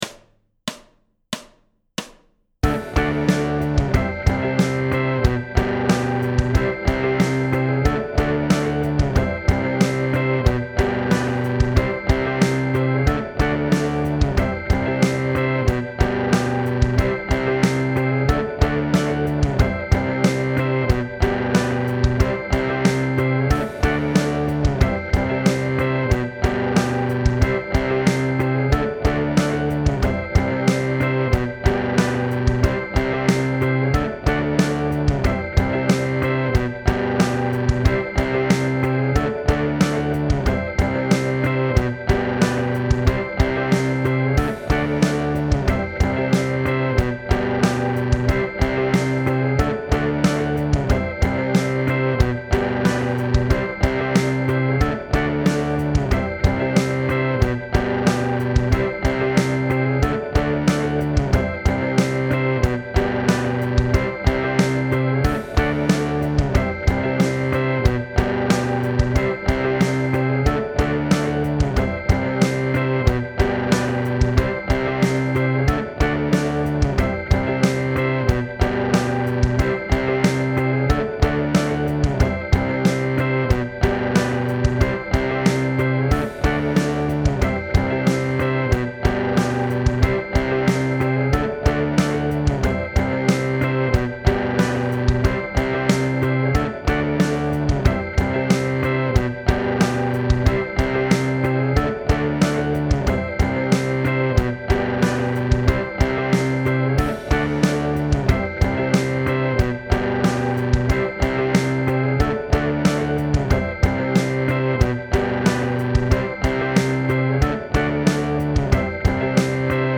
Bluesy 12/8 Practice Track - Chords: C - G - F - C - G 4-On-The-Floor Practice Track - Chords: G - Bb - C - G 'Southern Rock' Practice Track: D - C - G - C
Southern-Rock-Practice-Track-D-C-G-C.mp3